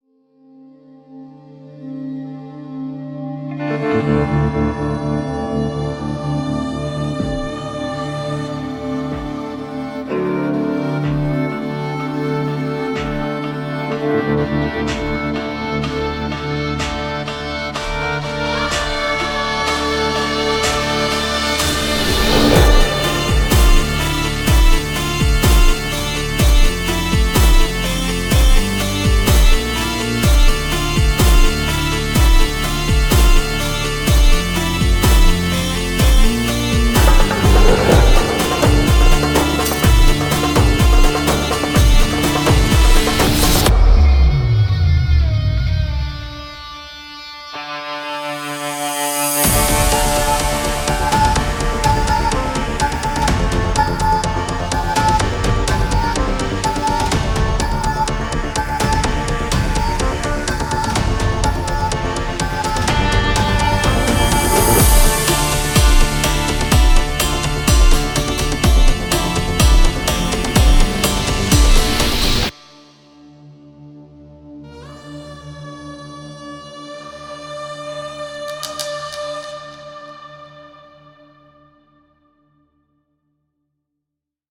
tema dizi müziği, duygusal heyecan aksiyon fon müziği.